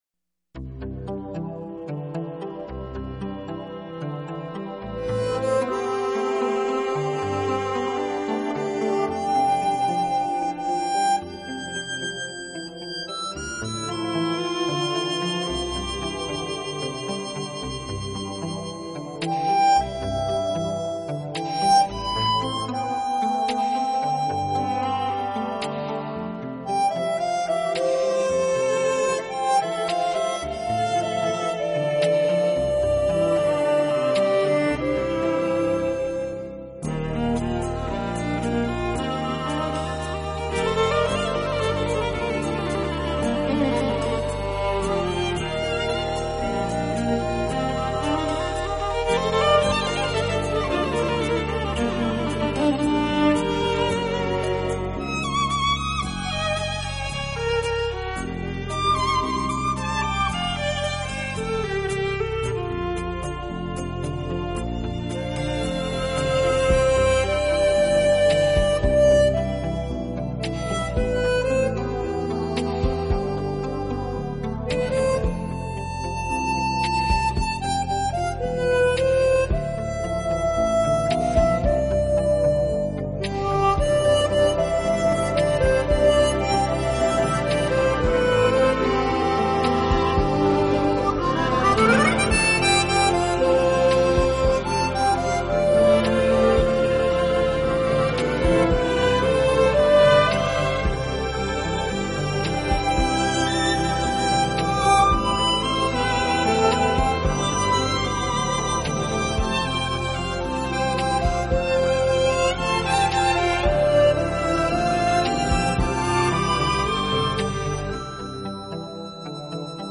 背景中如天鹅绒般的管弦乐、光泽剔透的吉他、线条分明的打击乐以及柔美如波
电子口琴哦，你的音响能听出来否？